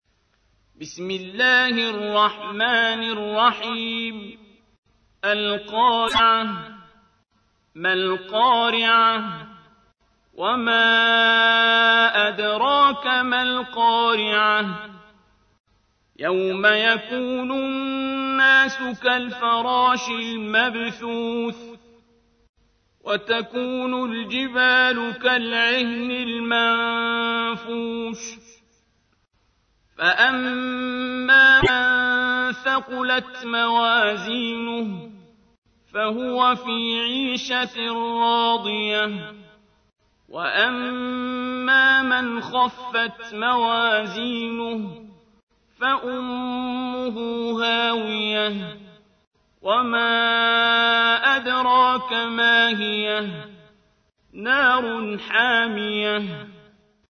تحميل : 101. سورة القارعة / القارئ عبد الباسط عبد الصمد / القرآن الكريم / موقع يا حسين